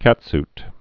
(kătst)